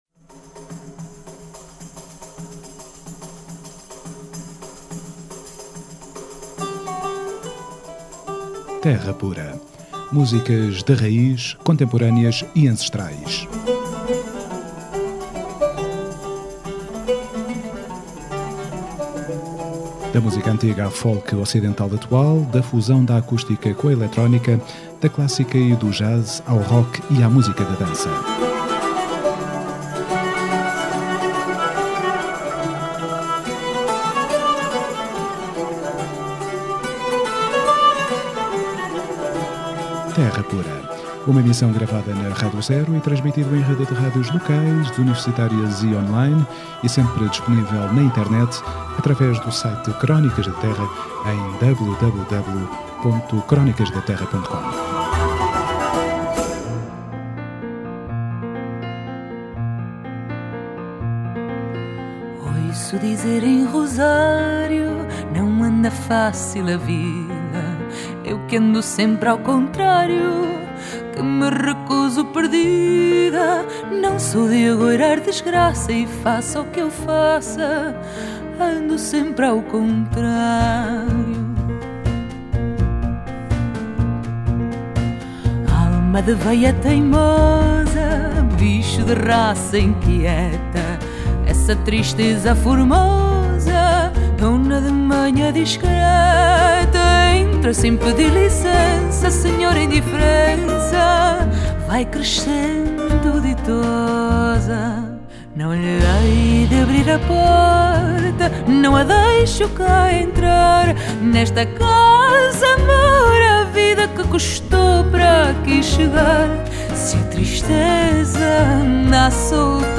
Terra Pura 27NOV13: Entrevista Mafalda Arnauth